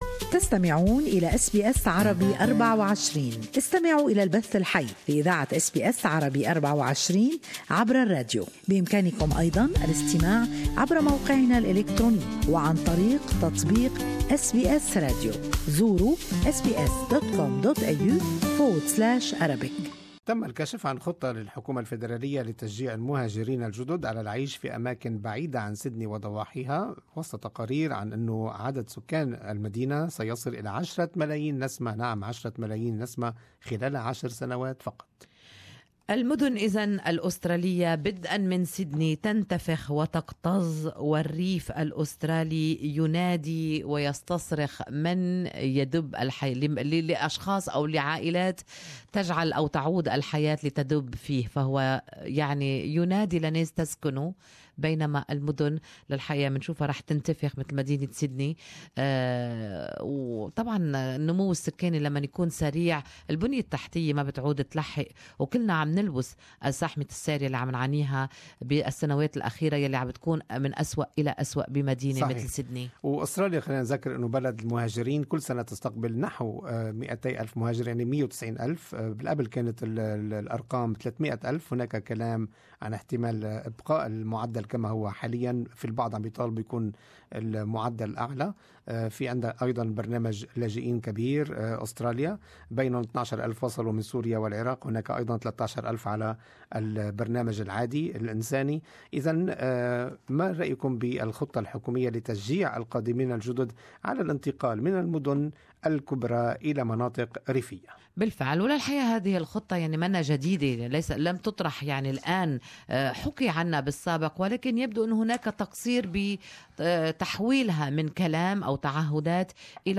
Our live talk back session from the Good Morning Australia program.